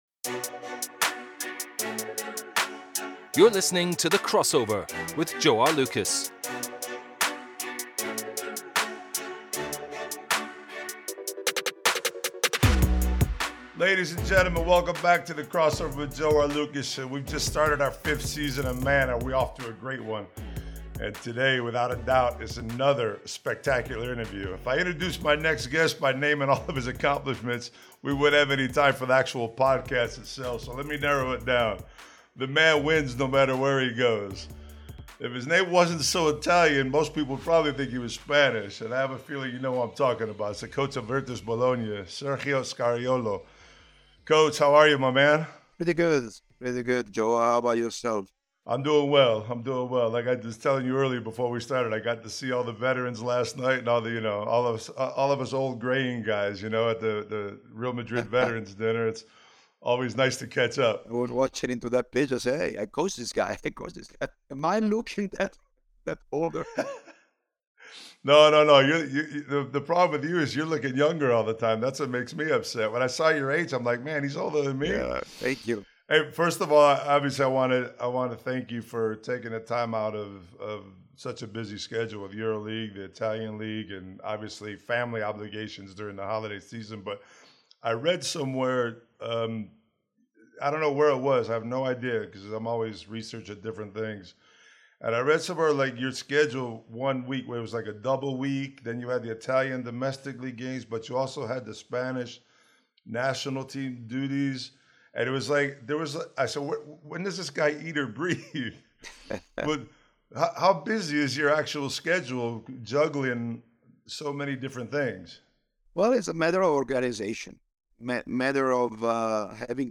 Joe Arlauckas’ next guest on The Crossover is Virtus Bologna’s Head Coach, Sergio Scariolo. The pair delve into Sergio’s early life in Brescia and how he wound up coaching basketball in Italy.
He recalls his years with Baskonia, Real Madrid, and his 5 year tenure in Malaga, as well as his success with the Spanish national team. In this wide ranging conversation, Sergio talks coaching philosophy, managing big names, and life in general, plus he takes ‘The Crossover Test’.